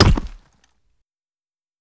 Bullet_Sheild_Hit.wav